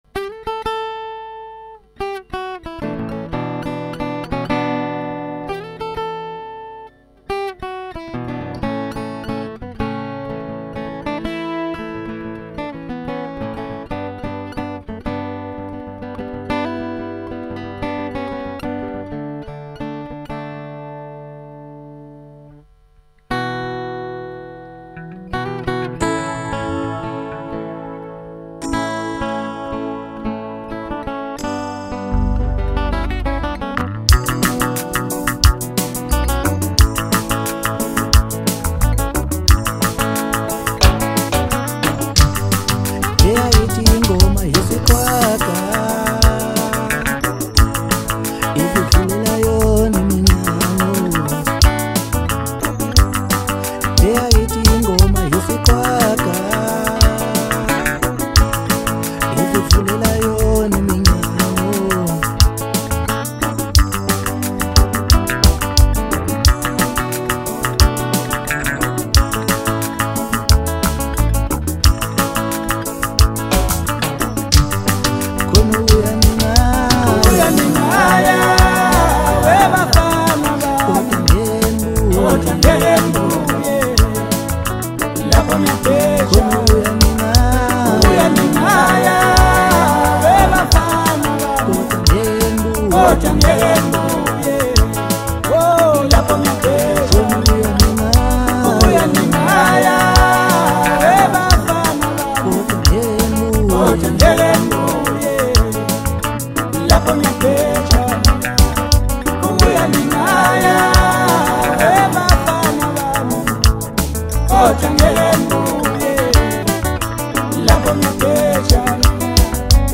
Home » Maskandi » DJ Mix
South African singer-songsmith